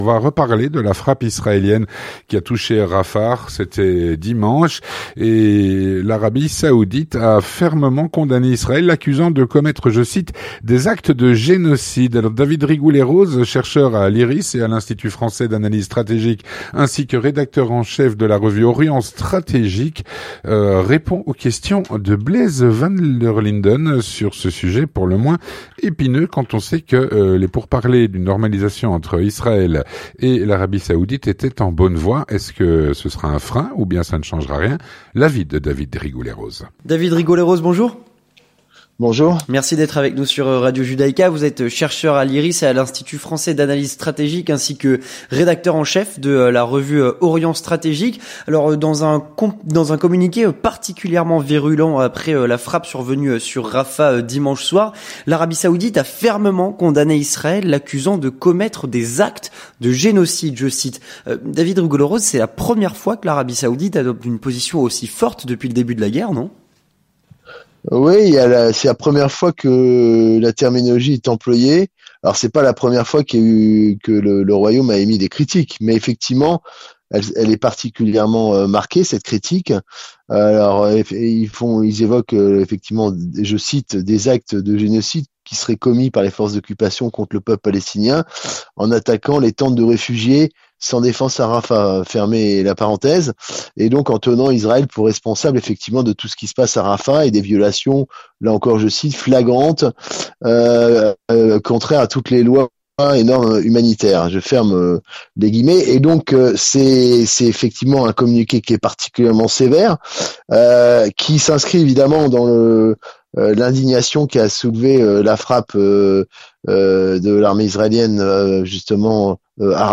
L'entretien du 18H - Suite à la frappe israélienne sur Rafah, l'Arabie saoudite a fermement condamné Israël, l'accusant de commettre des "actes de génocide".